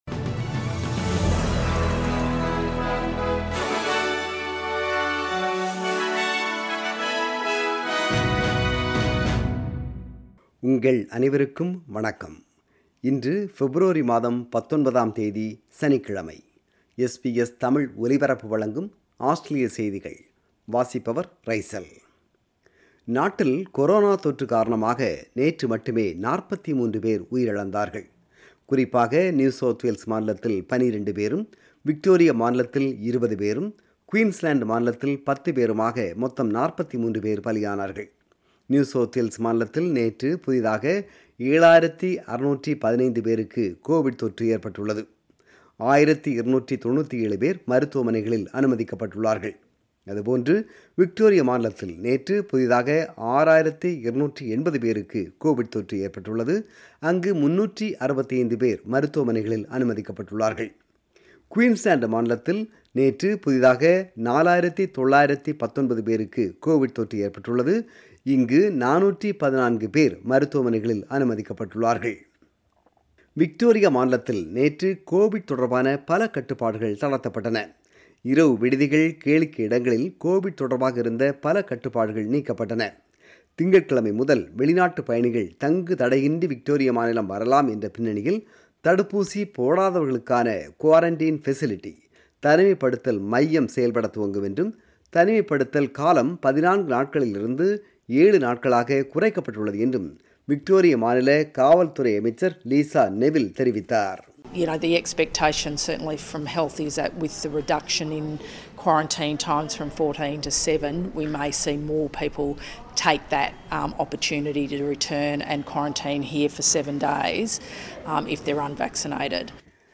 Australian News: 19 February 2022 – Saturday